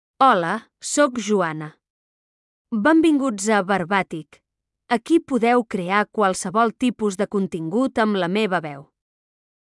Joana — Female Catalan AI voice
Joana is a female AI voice for Catalan.
Voice sample
Female
Joana delivers clear pronunciation with authentic Catalan intonation, making your content sound professionally produced.